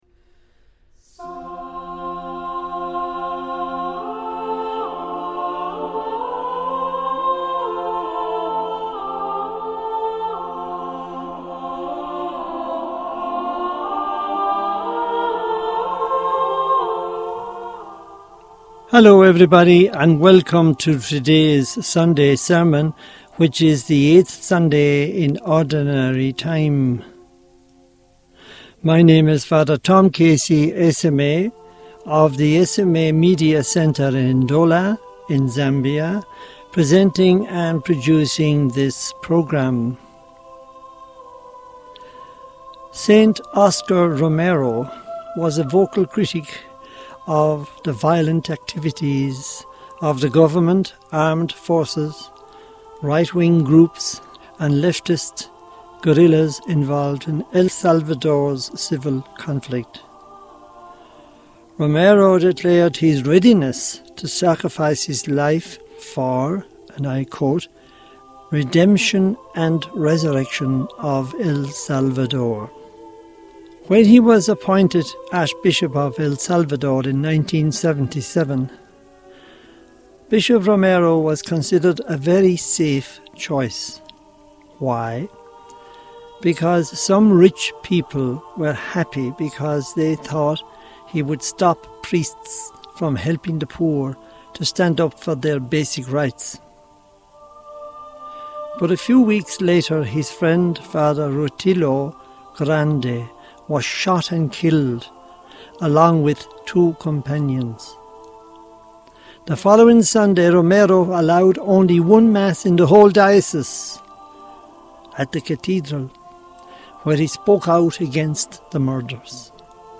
Homily for the 8th Sunday Homily of Ordinary Time: 2025